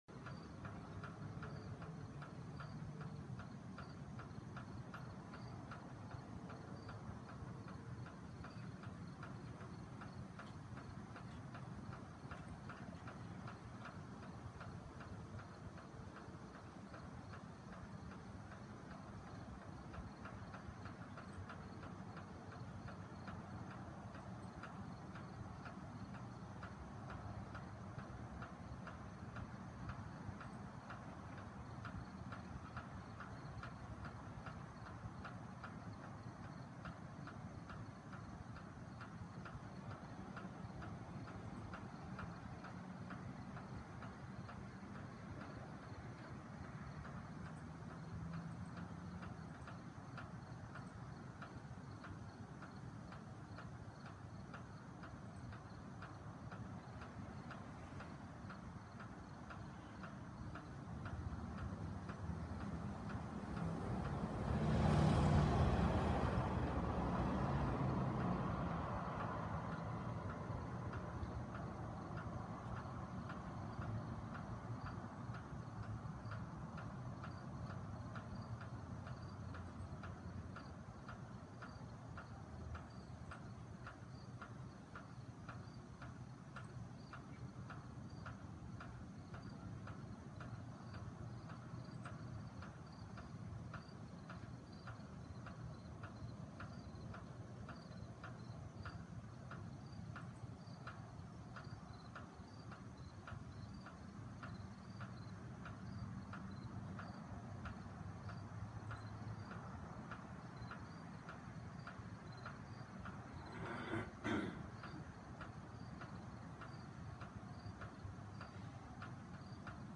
环境 雨水沟 蟋蟀 汽车 02
描述：新泽西州。在住宅区街道附近的房子的窗户上记录在Ipad mini上。在雨水沟落水管中取水。蟋蟀在背景中非常柔软。街上有几辆车。
标签： 传球 蟋蟀 户外 轻敲 汽车 现场记录 天沟
声道立体声